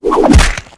wav / general / combat / dragons / bash1.wav
bash1.wav